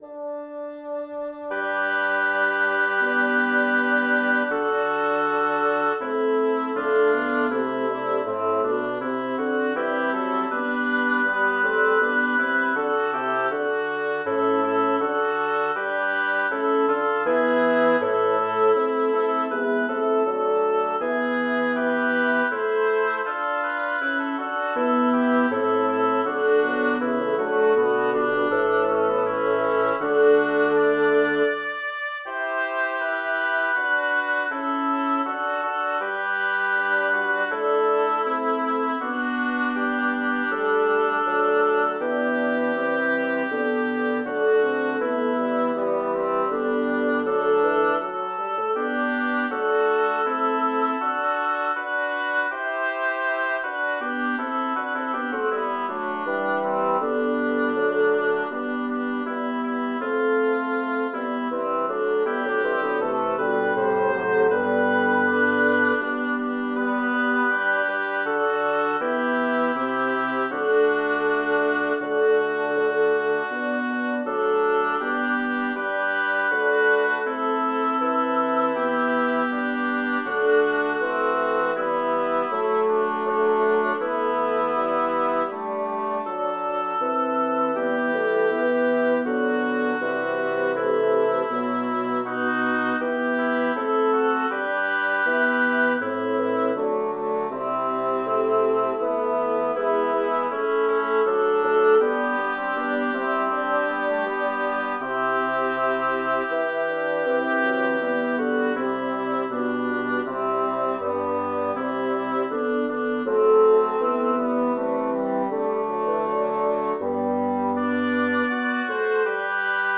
Title: Febre ond'hor per le vene Composer: Giovanni Pierluigi da Palestrina Lyricist: Number of voices: 5vv Voicing: SATTB Genre: Secular, Madrigal
Language: Italian Instruments: A cappella